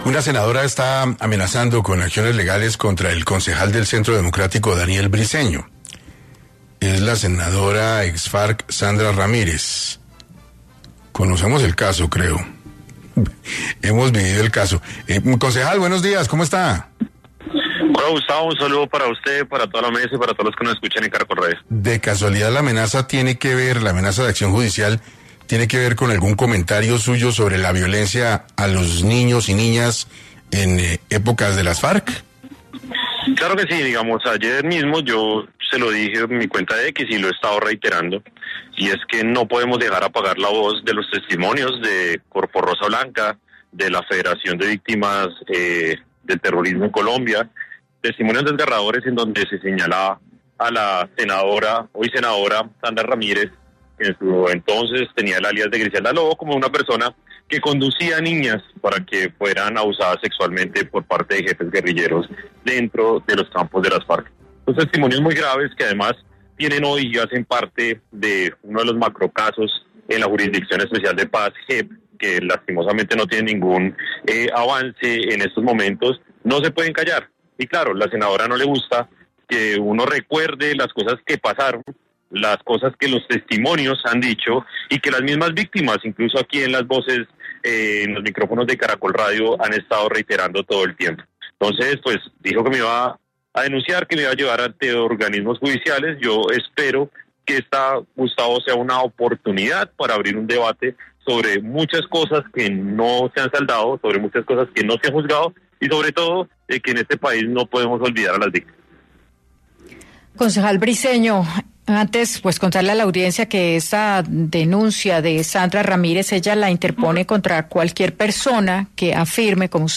En Caracol Radio estuvo el concejal de Bogotá, Daniel Briceño, quien explicó los motivos por los que podría ser denunciado